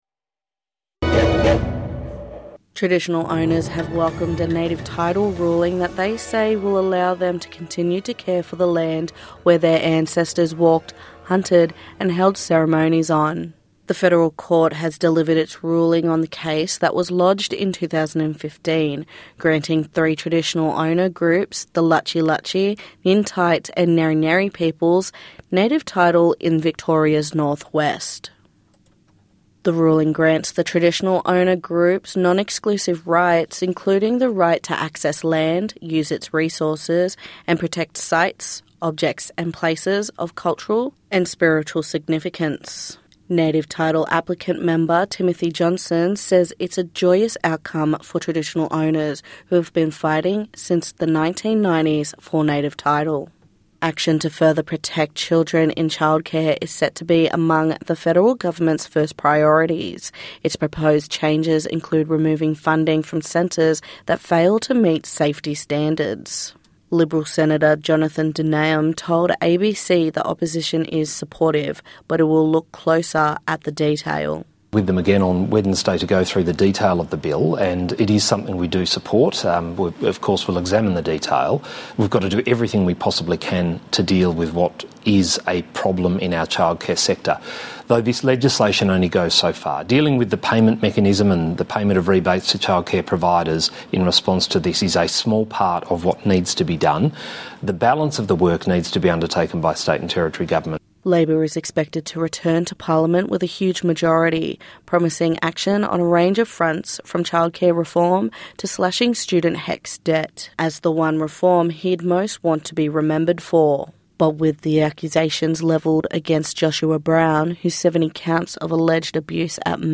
NITV Radio News 21/07/2025